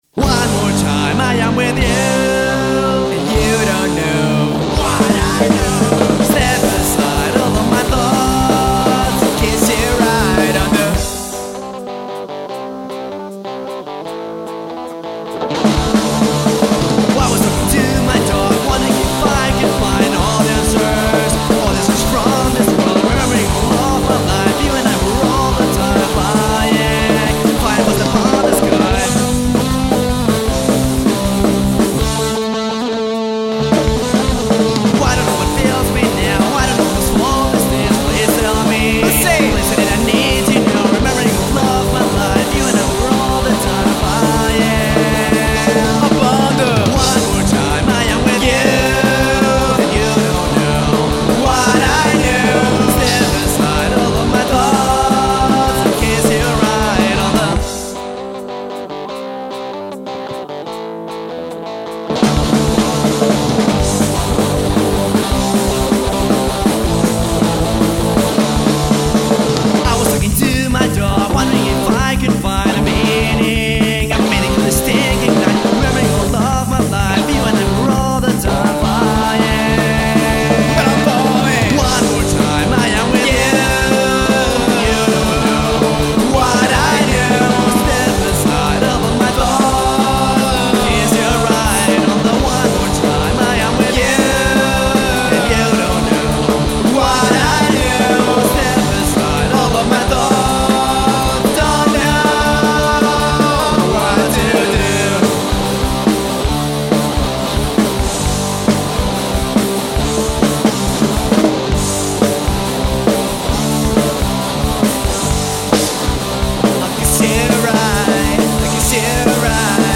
acústica